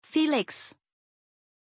Pronuncia di Feliks
pronunciation-feliks-zh.mp3